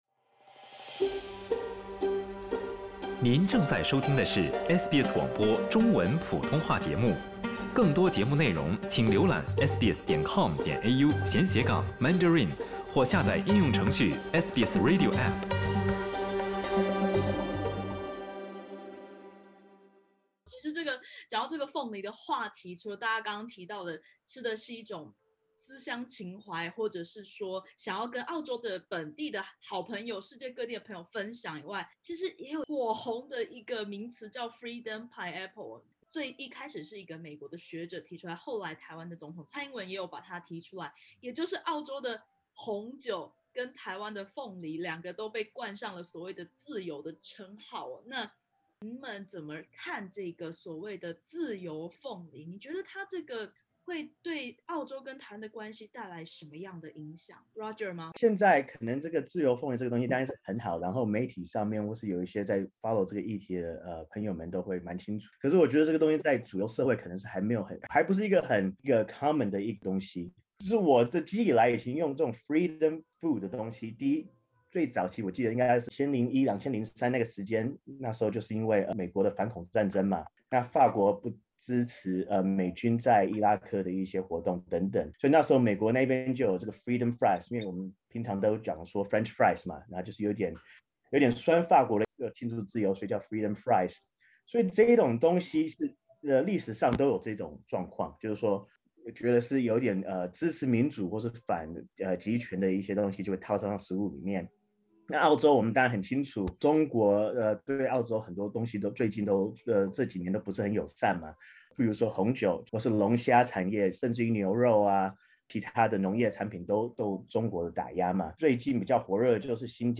来自台湾的凤梨，打着“自由凤梨”的名号进口澳大利亚，本地台湾社区怎麽想？点击首图收听采访音频。